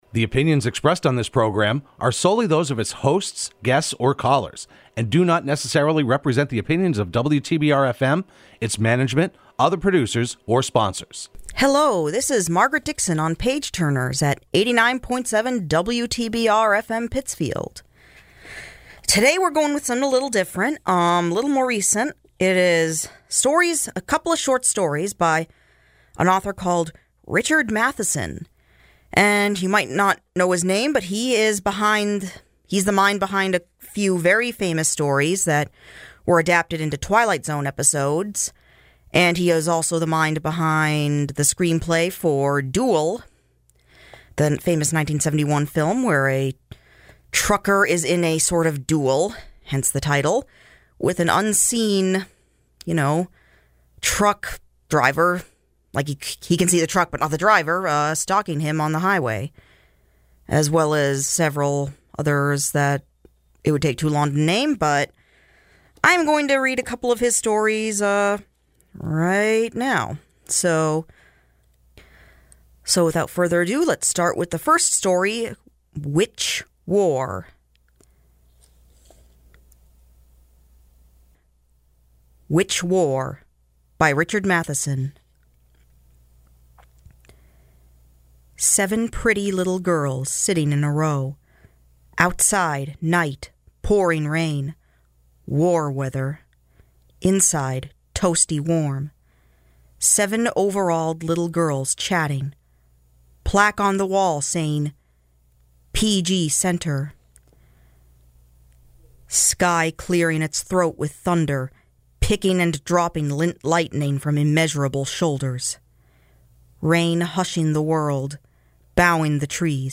reads two short stories